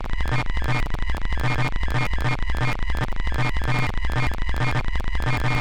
sci-fi machine 2 (printer-like)
device electronic machine printer science-fiction sci-fi sound effect free sound royalty free Memes